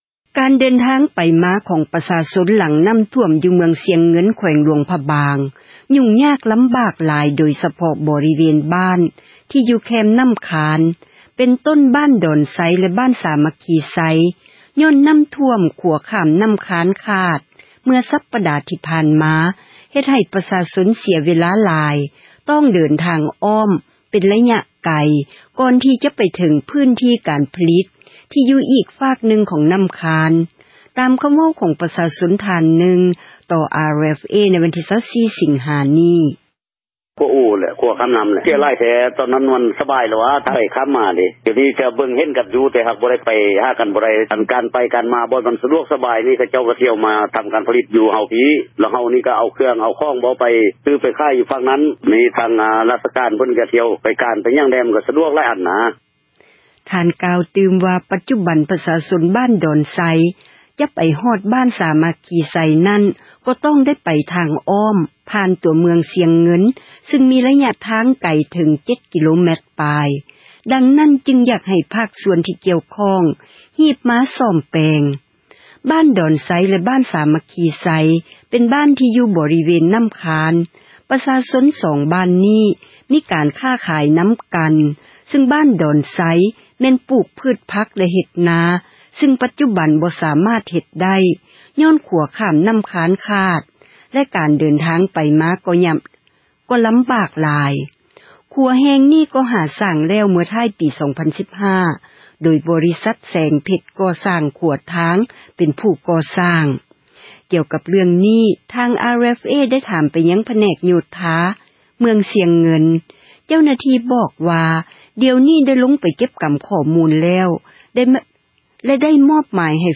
ການສັນຈອນໄປມາ ຂອງປະຊາຊົນ ຫລັງນໍ້າຖ້ວມ ຢູ່ເມືອງຊຽງເງິນ ແຂວງ ຫຼວງພຣະບາງ ຫຍູ້ງຍາກ ລໍາບາກຫຼາຍ ໂດຍສະເພາະ ບໍຣິເວນ ບ້ານ ທີ່ຢູ່ແຄມນໍ້າຄານ ເປັນຕົ້ນ ບ້ານດອນໄຊ ແລະ ບ້ານສາມັກຄີໄຊ ຍ້ອນນໍ້າຖ້ວມ ຂົວຂ້າມ ນໍ້າຄານ ຂາດ ເມື່ອ ສັປດາ ທີ່ຜ່ານມາ ເຮັດໃຫ້ ປະຊາຊົນ ເສັຽເວລາ ຕ້ອງໃຊ້ທາງອ້ອມ ໄປໄກ ກ່ອນຈະໄປເຖິງ ພື້ນທີ່ ການຜລິດ ຫາຢູ່ຫາກິນ ທີ່ຢູ່ອີກ ຟາກນຶ່ງ ຂອງນໍ້າຄານ. ຕາມຄໍາເວົ້າ ຂອງ ປະຊາຊົນ ທ່ານນຶ່ງ ຕໍ່ RFA ໃນວັນທີ 24 ສິງຫາ ນີ້: